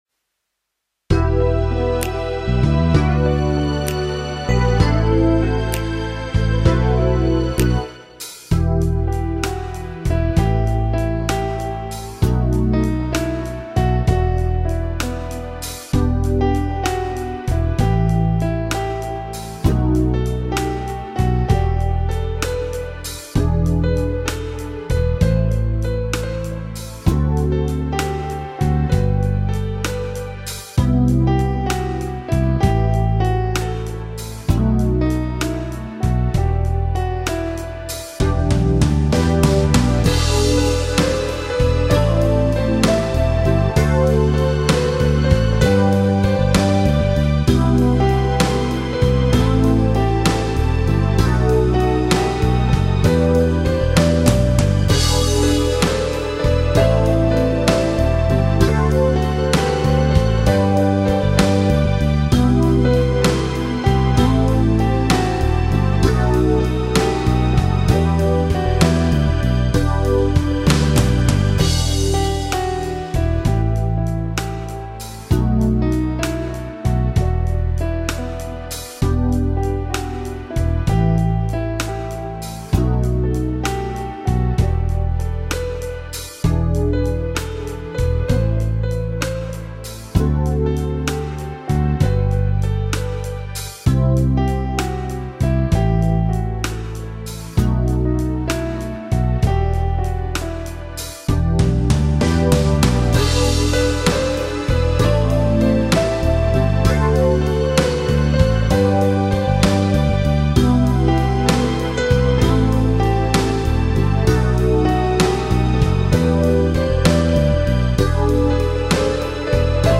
спини цю мить...-ф змелодією.mp3